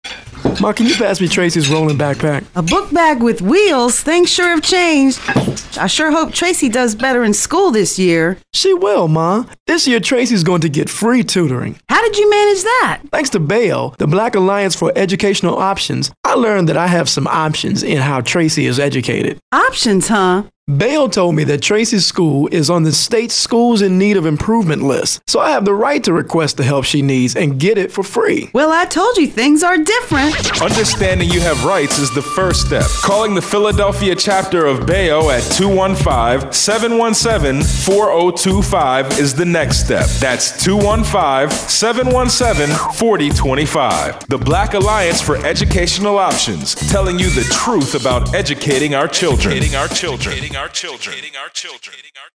Philadelphia Radio Ad (MP3)
(PCI-12)Philadelphia_Radio_Ad.MP3